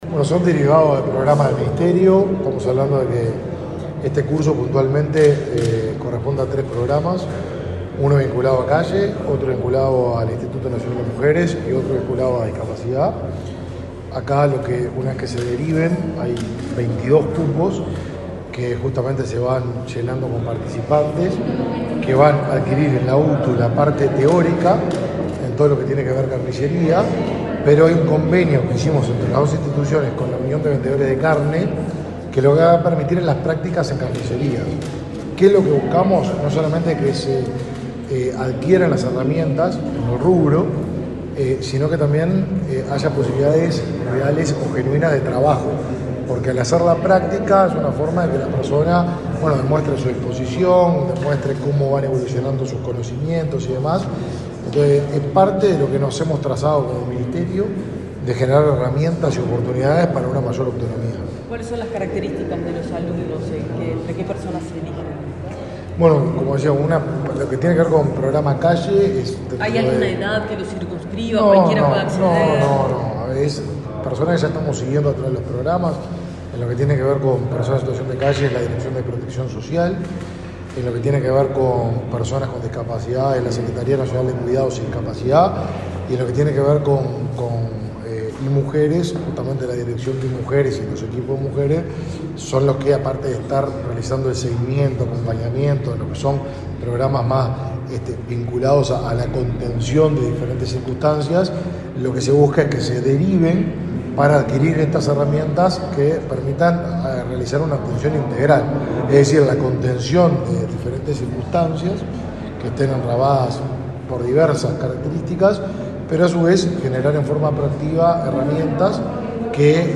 Declaraciones del ministro Martín Lema
El ministro de Desarrollo Social, Martín Lema, dialogó con la prensa luego de participar del acto de comienzo de cursos de UTU para carniceros.